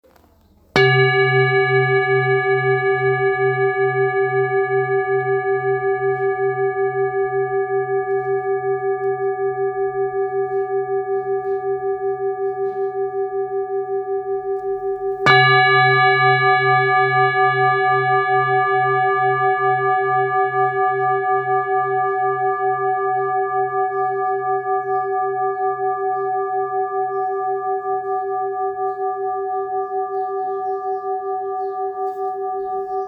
Singing bowl, Buddhist Hand Beaten, Moon carved, Antique Finishing, Select Accessories, 25 by 25 cm,
Material Seven Bronze Metal
This is a Himalayas handmade full moon singing bowl. The full moon bowl is used in meditation for healing and relaxation sound therapy.